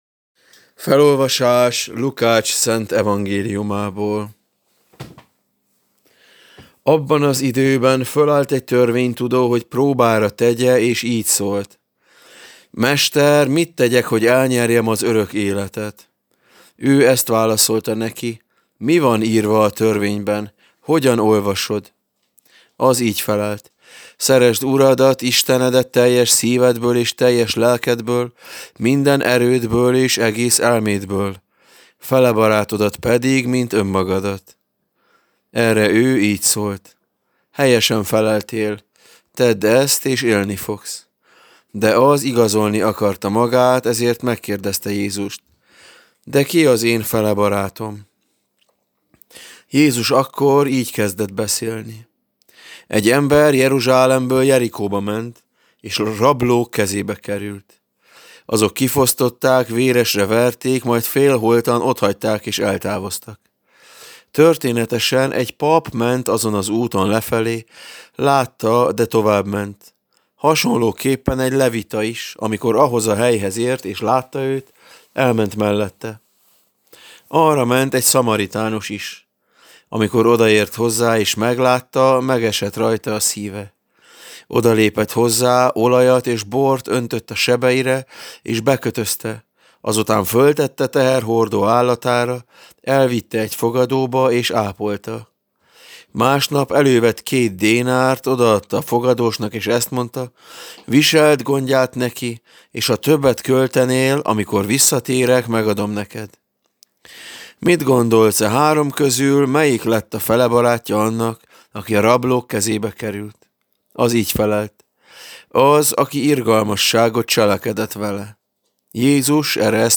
Evangéliumi olvasmány, Lk 10,25-37: